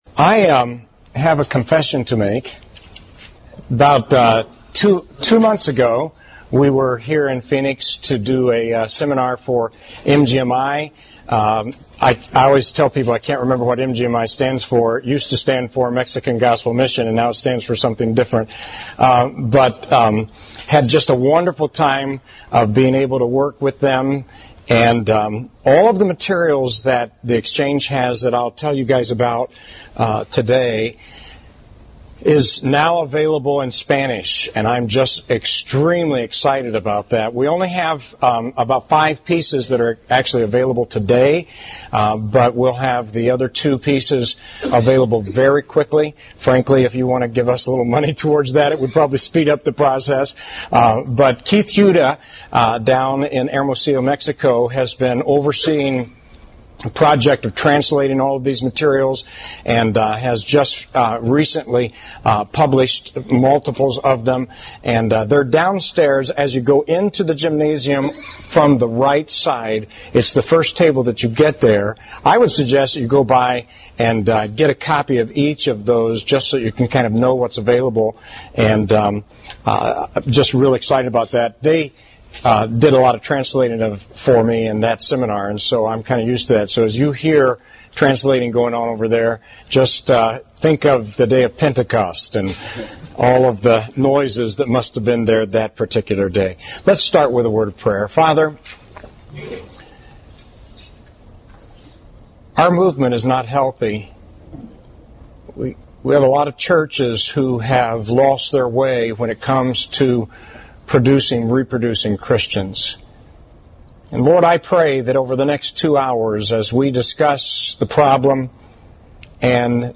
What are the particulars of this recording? Series: FBFI National Fellowship: Workshop